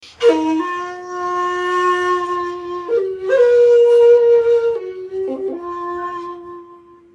Shakuhachi 52